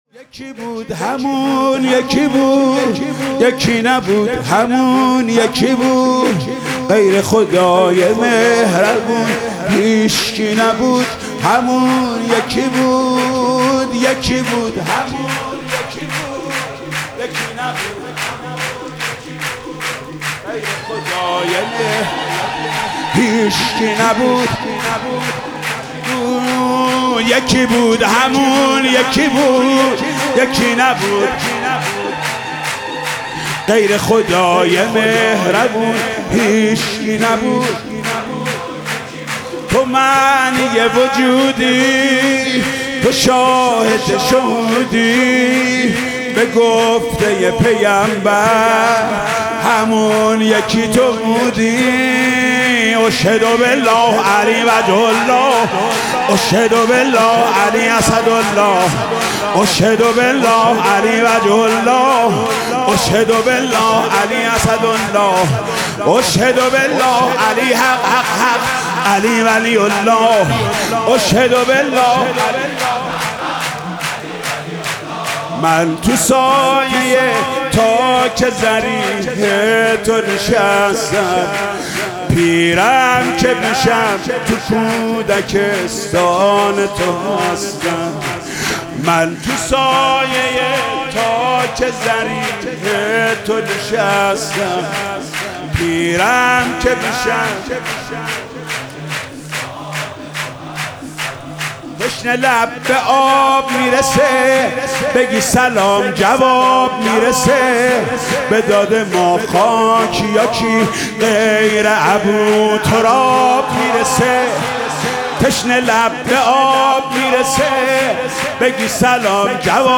ولادت حضرت امیرالمومنین علی (ع) 1403